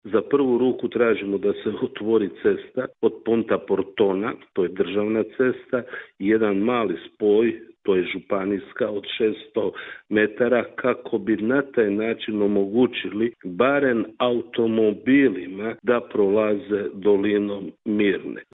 Kajin je dodao kako je sastanak, zbog ozbiljnosti situacije, bio konstruktivan i kako su njihovi zahtjevi vrlo jasni: